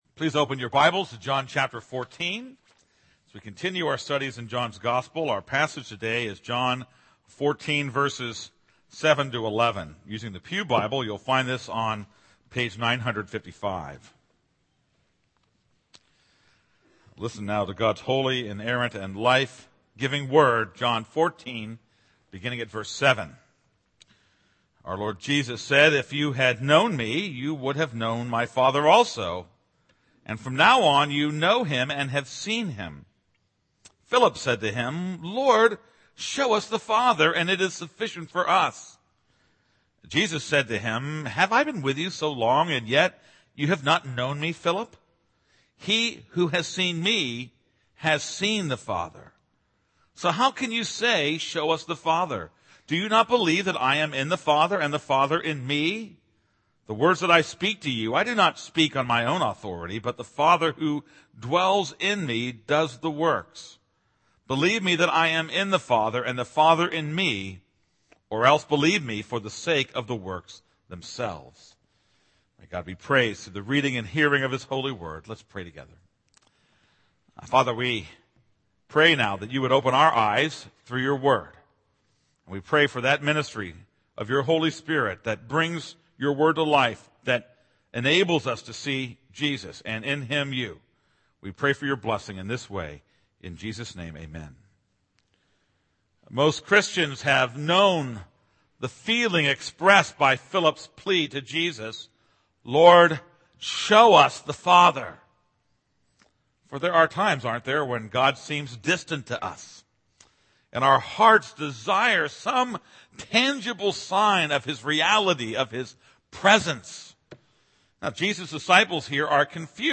This is a sermon on John 14:7-11.